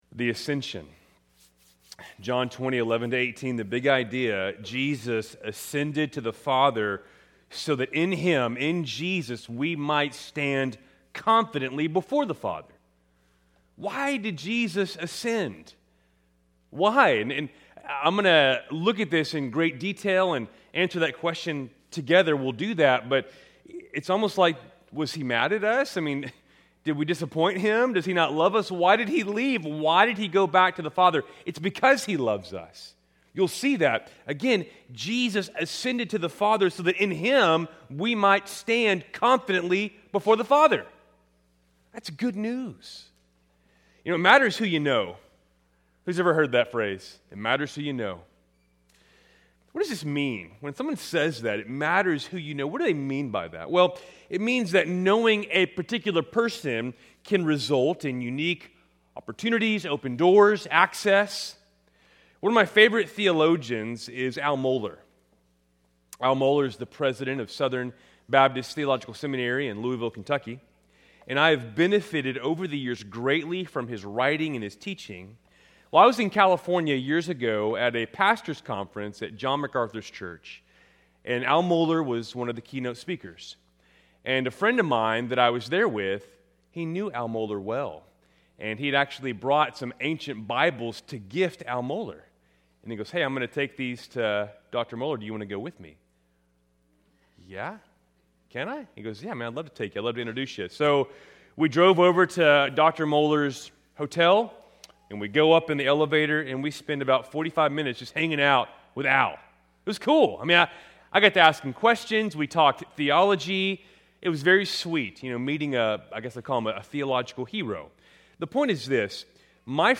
Keltys Worship Service, March 8, 2026